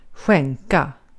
Ääntäminen
Vaihtoehtoiset kirjoitusmuodot gyve Synonyymit cede yield donate bend pass move guess estimate transfer flex predict resilience gift impart depict Ääntäminen : IPA : /ˈɡɪv/ US : IPA : [ɡɪv] UK brittisk engelska